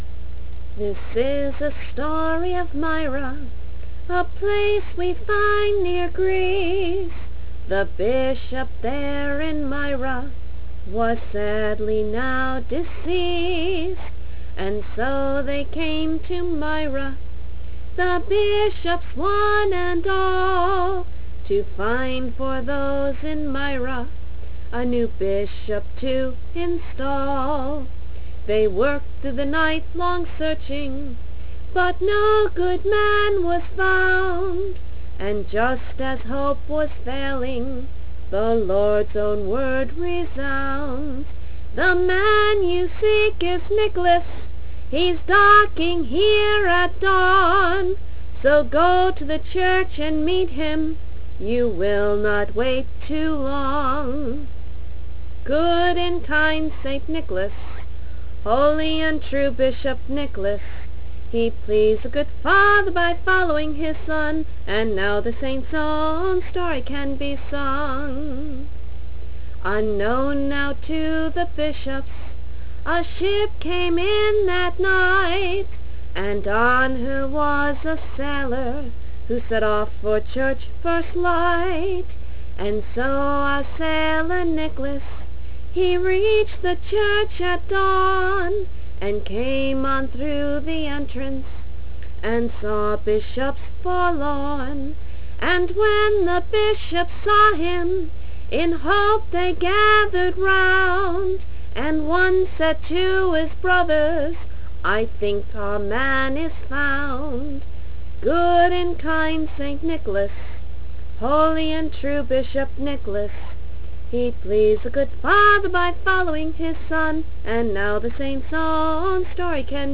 Below is a song we wrote in attempt to tell one of these stories, the first which describes how Saint Nicholas became the Bishop of Myra.